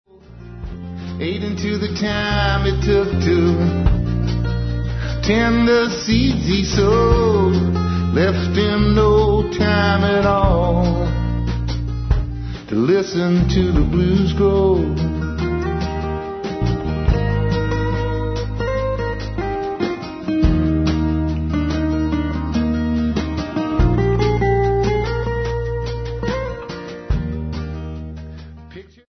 lead vocals, guitar, dulcimer
Recorded at General Store Recording